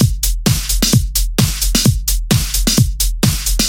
Tag: 130 bpm House Loops Drum Loops 636.10 KB wav Key : Unknown